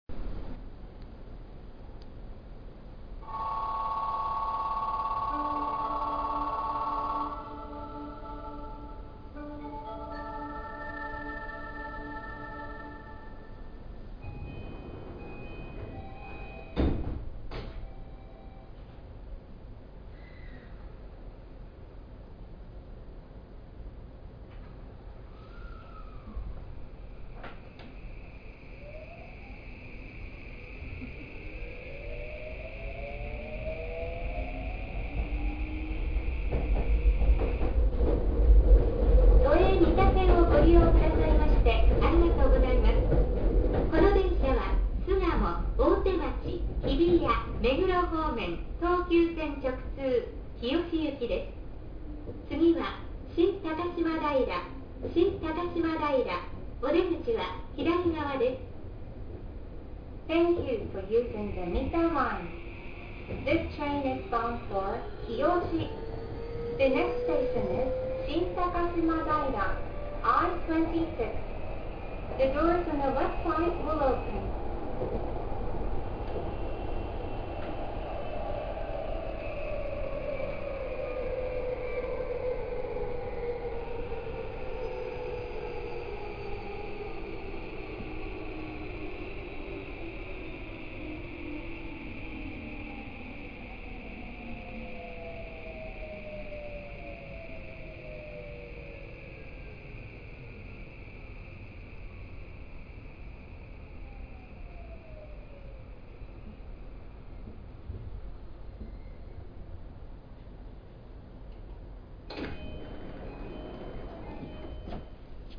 ・3020系走行音
【都営三田線】西高島平→新高島平
2020系・6020系同様の三菱SiCとなっています。というわけで、SiCの中では派手な音を立てる方で聞きごたえはあると言えそうです。
3020_NishiTakashima-ShinTakashima.mp3